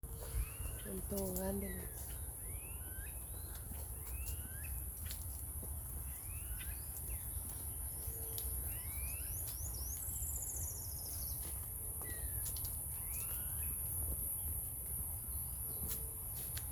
Anu-preto (Crotophaga ani)
Nome em Inglês: Smooth-billed Ani
Localidade ou área protegida: Reserva Privada San Sebastián de la Selva
Condição: Selvagem
Certeza: Observado, Gravado Vocal